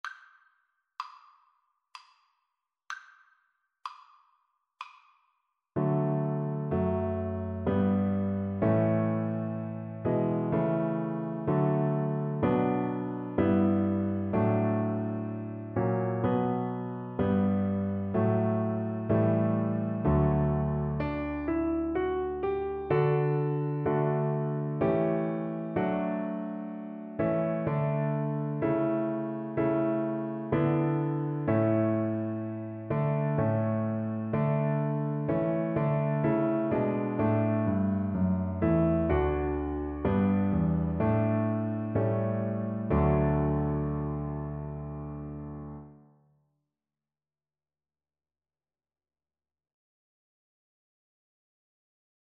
Cello
3/4 (View more 3/4 Music)
D major (Sounding Pitch) (View more D major Music for Cello )
Traditional (View more Traditional Cello Music)